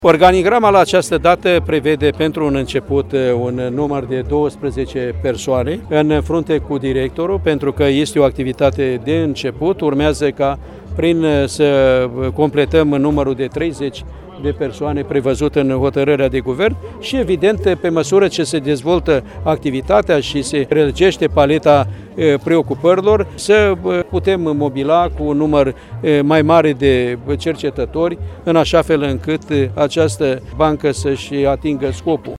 Organigrama unității de la Buzău prevede mai întâi 12 posturi, urmând  ca în timp, numărul cercetătorilor să crească, după cum a declarat ministrul Agriculturii.